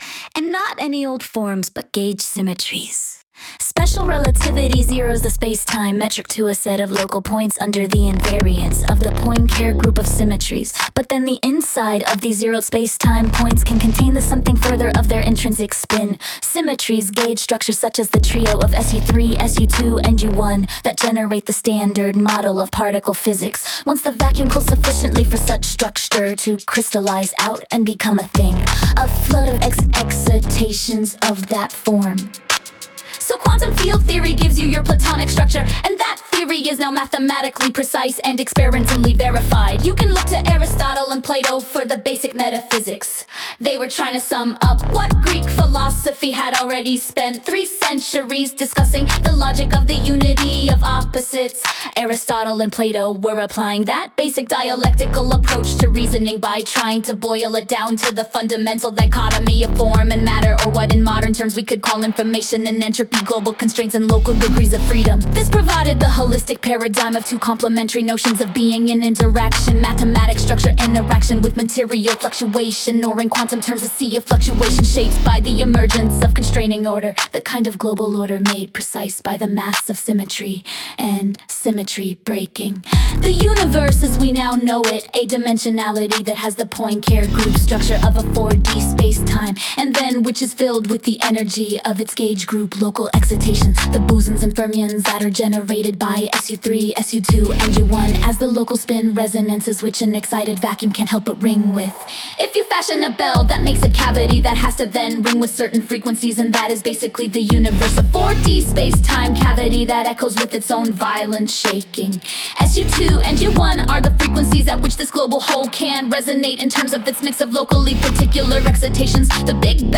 And the bombastic rap treatment works